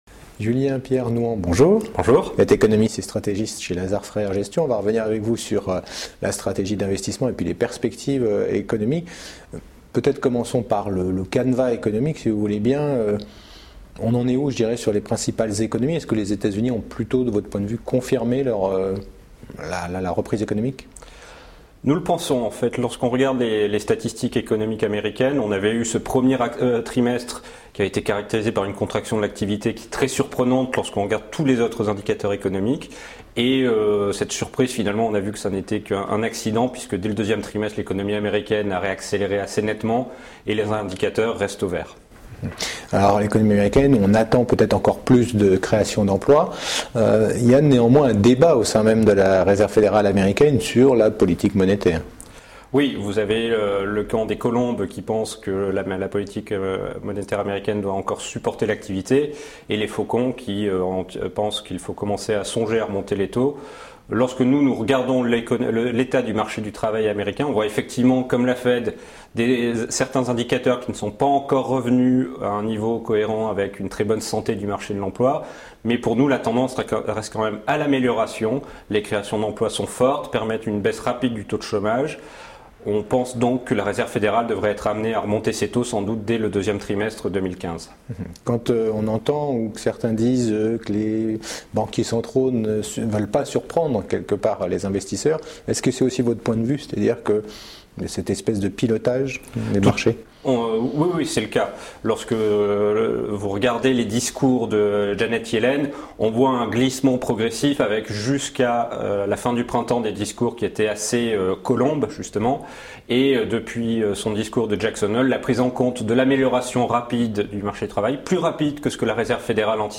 Cet interview a été tournée au Club Confair, 54 rue Laffite, 75009 Paris :